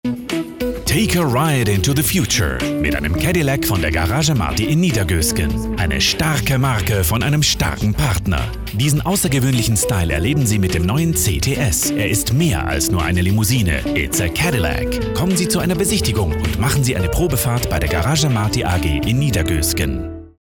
Werbung Hochdeutsch (CH)
Meistgebuchter Sprecher mit breitem Einsatzspektrum. Diverse Dialekte und Trickstimmen.